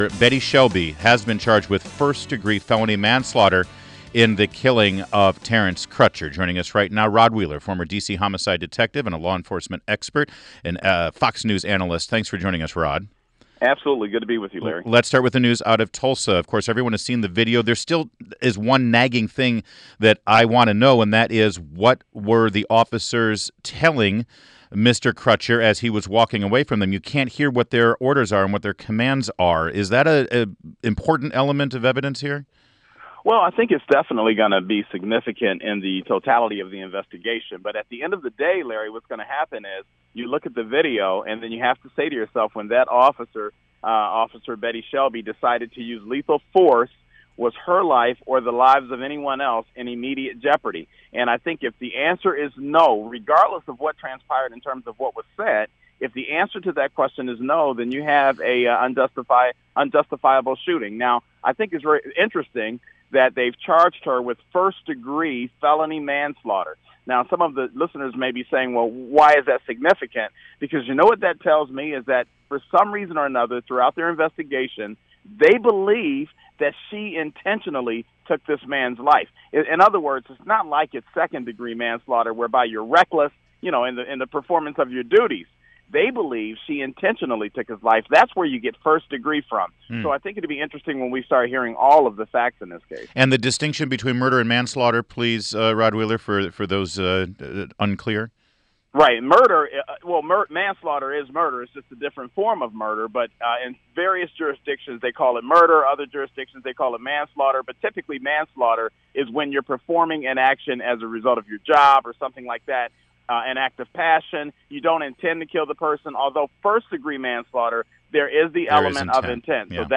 DRIVE AT FIVE INTERVIEW
Former DC Homicide Detective and law enforcement expert